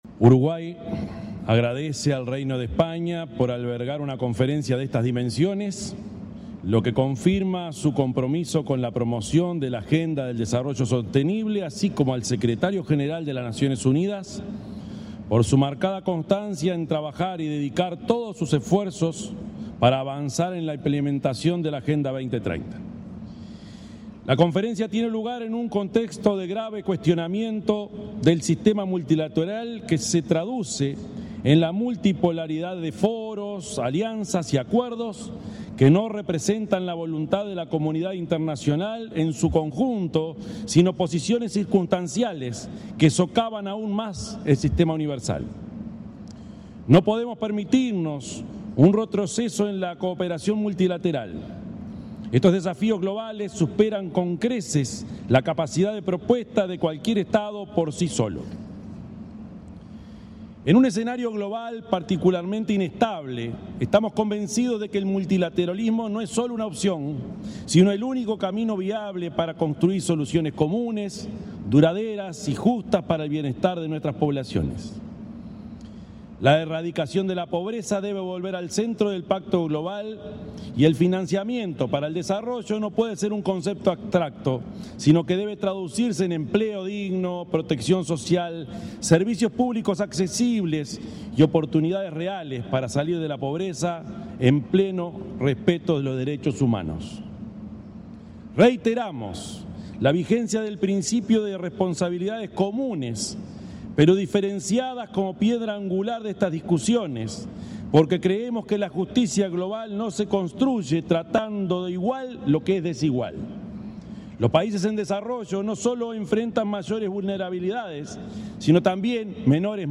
Declaraciones del presidente Yamandú Orsi durante una misión oficial en España
El presidente de la República, Yamandú Orsi, intervino en el debate general de la 4.ª Conferencia Internacional sobre Financiación para el Desarrollo,